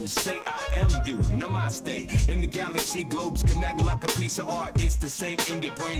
Hay incluso algún ejemplo de audio con una tasa de transferencia de 3kb/sec y con una calidad realmente alucinante para esa tasa de transferencia como podéis escuchar en el ejemplo siguiente:
fmi_0_encodec_hq_3kbps.wav